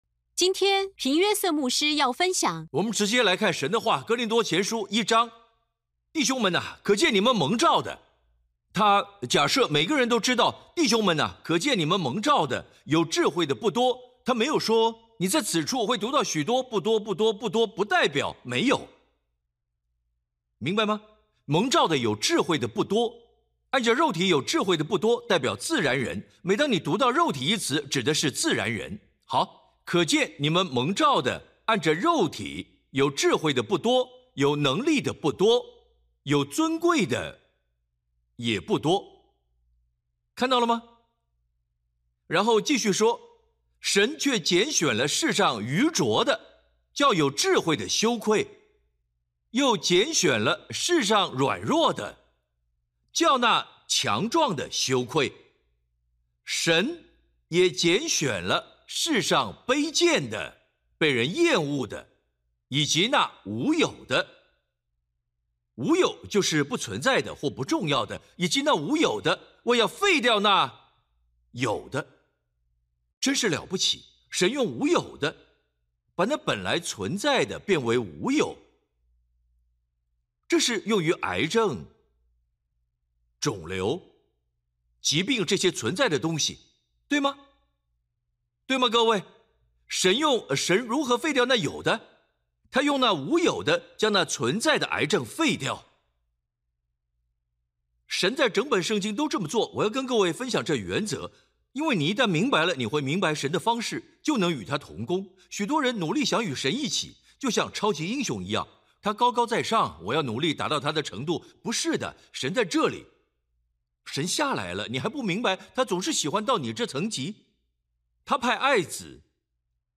软弱变刚强系列讲道